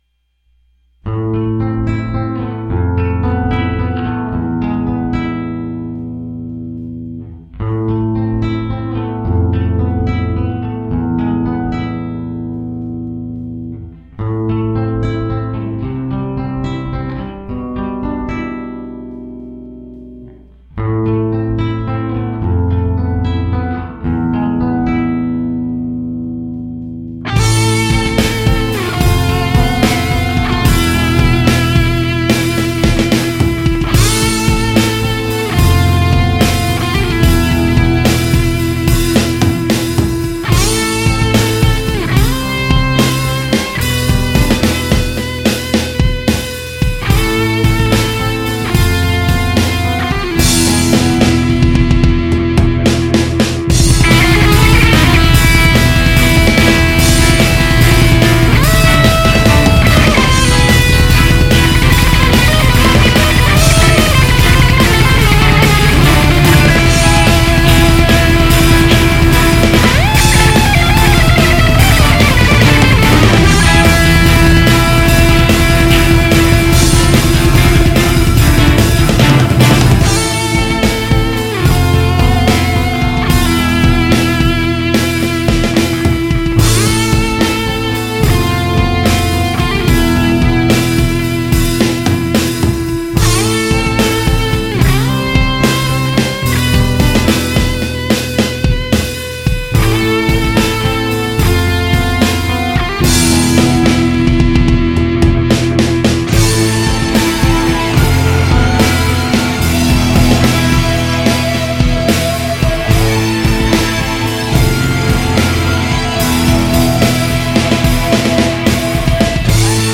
More Life (a flamenco-metal piece)